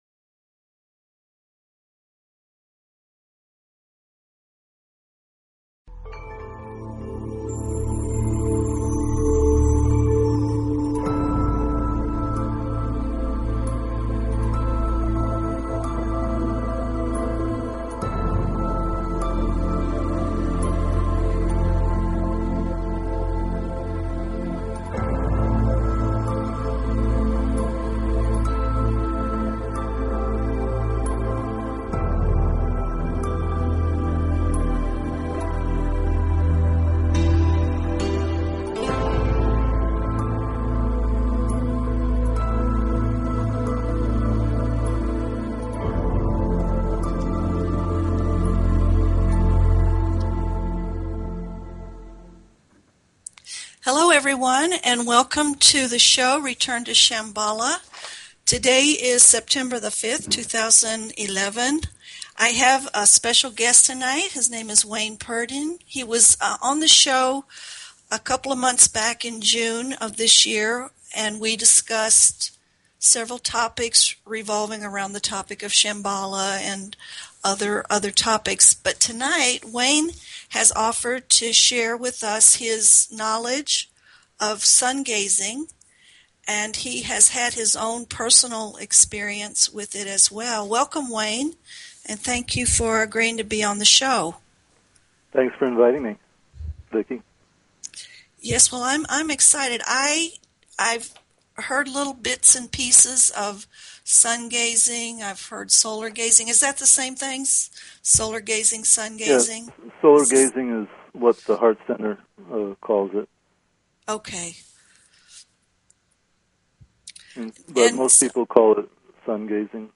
Talk Show Episode, Audio Podcast, Return_to_Shamballa and Courtesy of BBS Radio on , show guests , about , categorized as